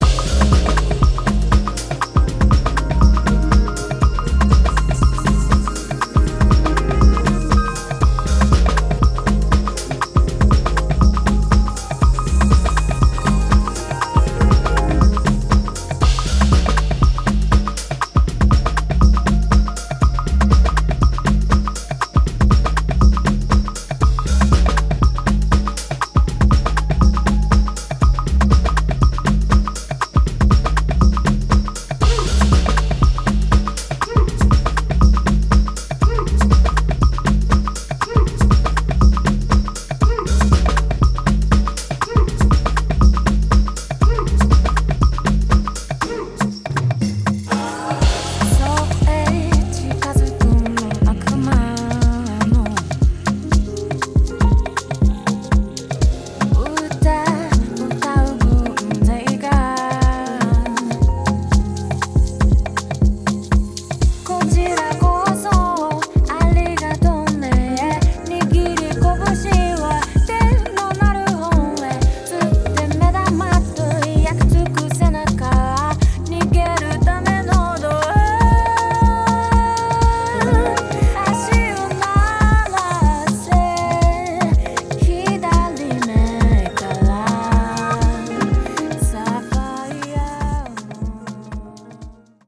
New Release House Techno